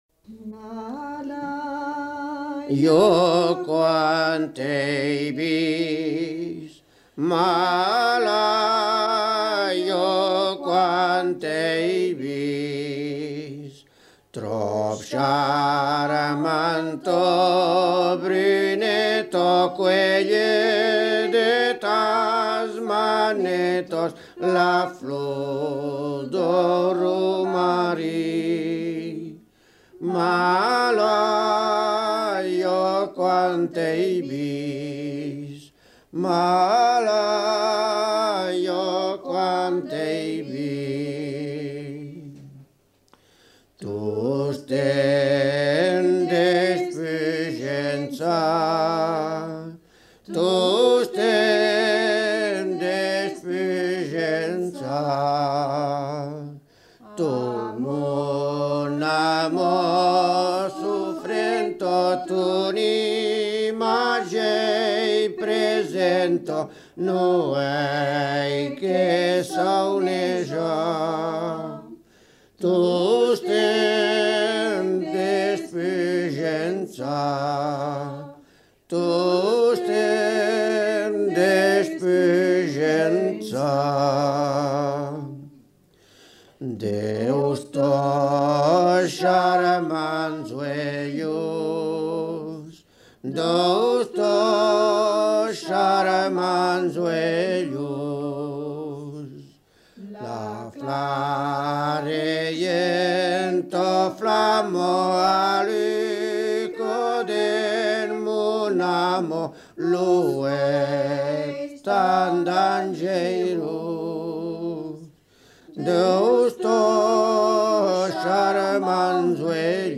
Aire culturelle : Béarn
Lieu : Bielle
Genre : chant
Type de voix : voix d'homme ; voix de femme
Production du son : chanté
Descripteurs : polyphonie